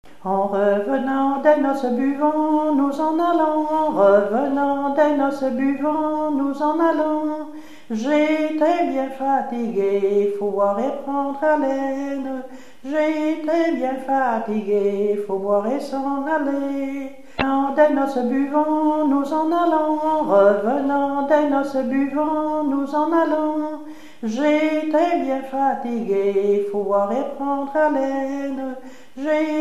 marche de cortège de noces
Pièce musicale inédite